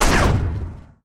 etfx_shoot_nuke.wav